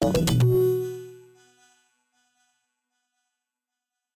sfx_transition-07.ogg